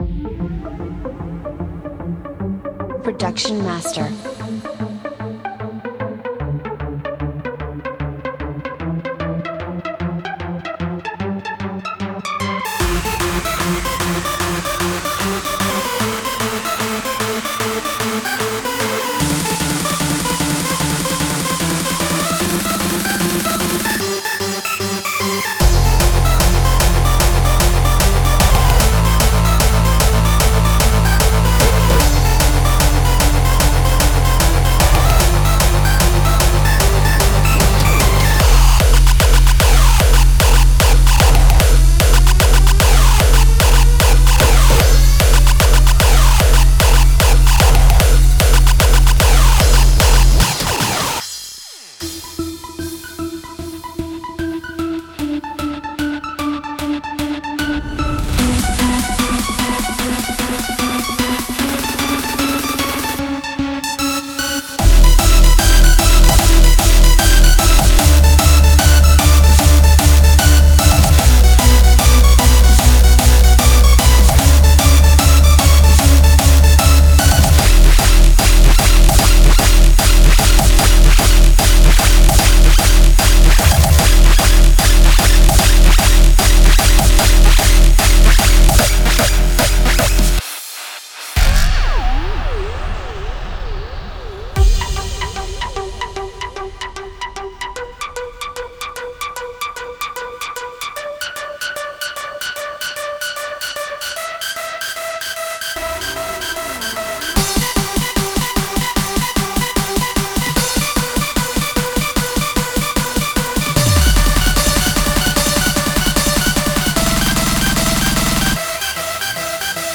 该包中的声音和音调已经通过各种失真效果，压缩器和踩踏盒进行了处理，并经过手工处理，以达到原始的中音驾驶效果。